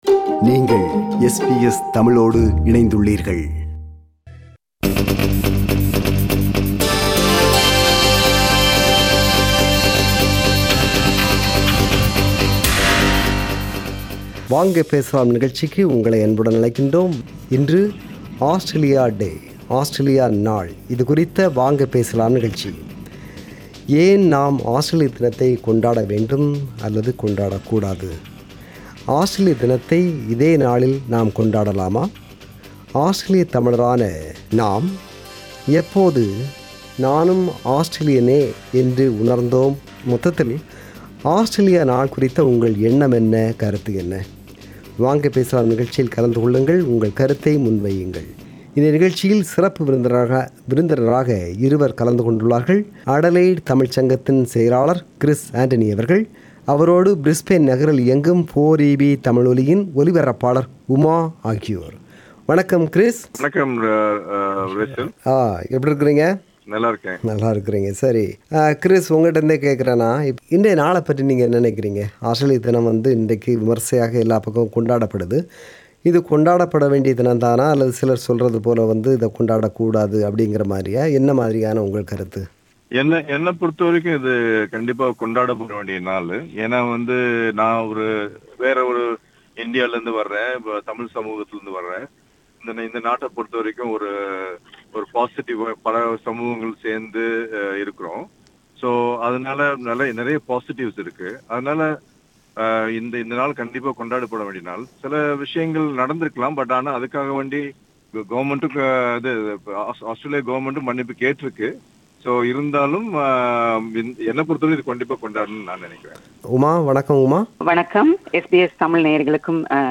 Australia Day special discussion